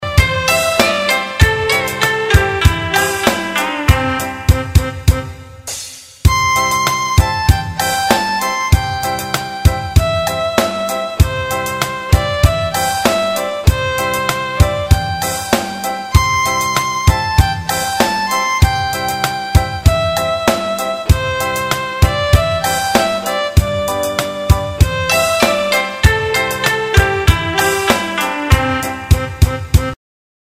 Bài hát "tập đếm"